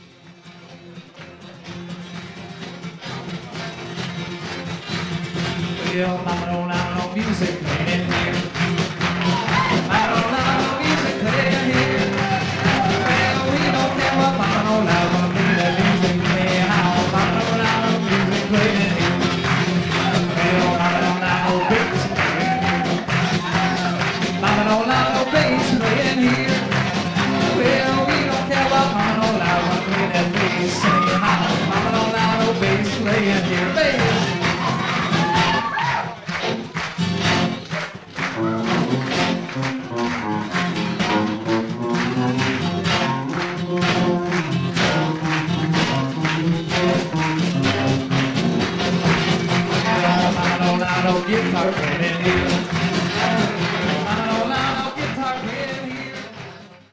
This was one of the few times when I performed with a band.